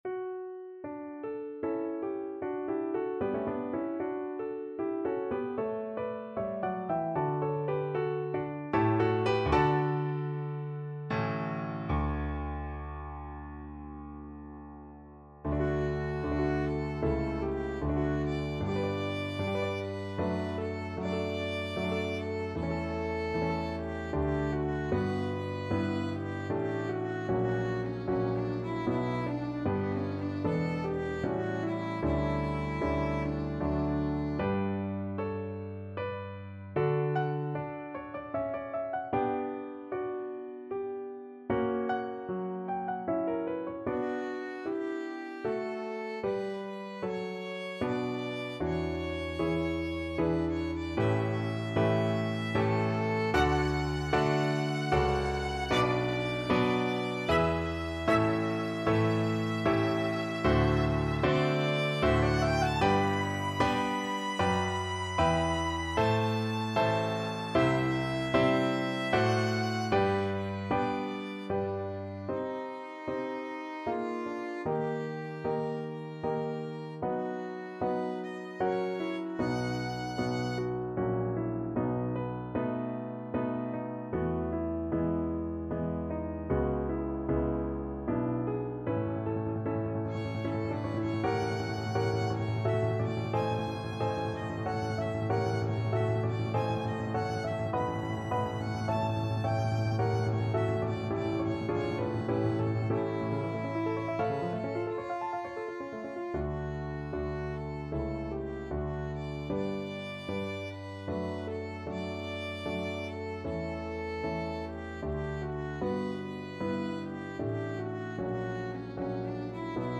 Free Sheet music for Violin
Play (or use space bar on your keyboard) Pause Music Playalong - Piano Accompaniment Playalong Band Accompaniment not yet available transpose reset tempo print settings full screen
3/4 (View more 3/4 Music)
Andante religioso (=80) =76
D major (Sounding Pitch) (View more D major Music for Violin )
Classical (View more Classical Violin Music)